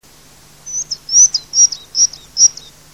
fsemitorquata.mp3